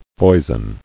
[foi·son]